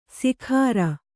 ♪ sikhāra